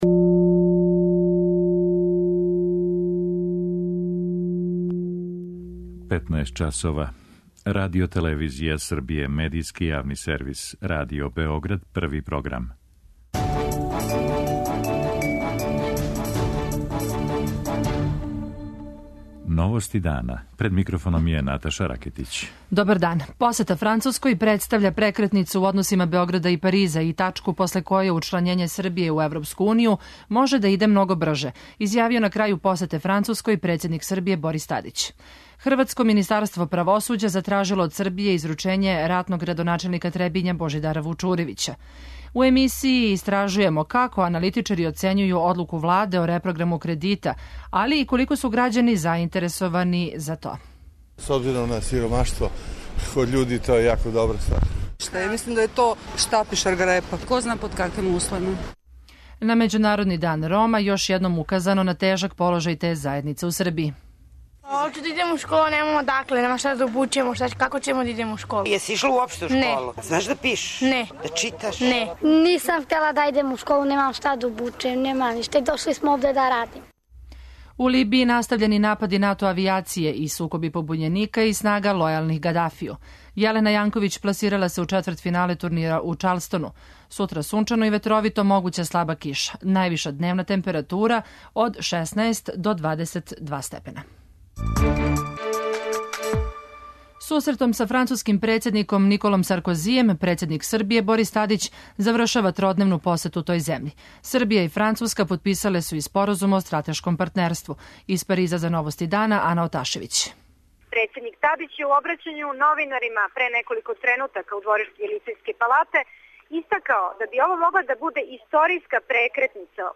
У емисији ћете чути репортажу која говори о животу Рома у Београду.